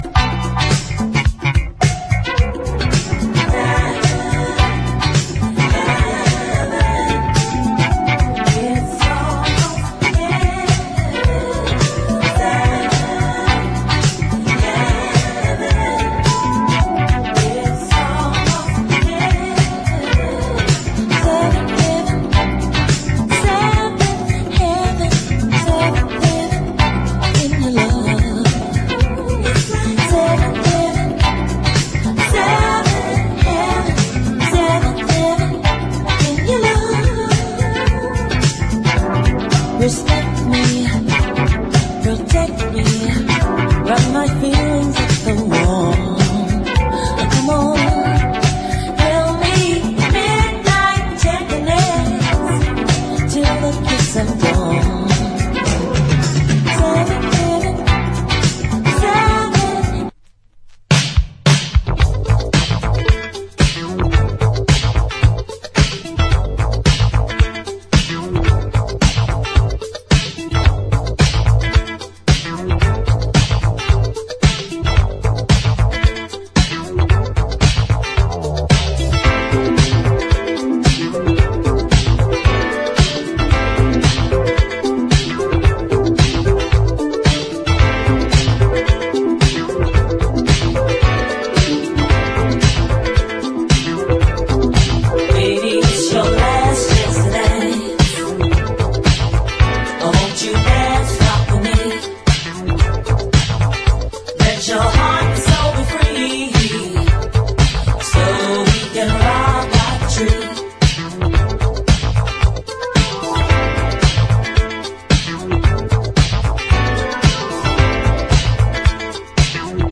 SOUL / SOUL / DISCO / ORGAN / EASY LISTENING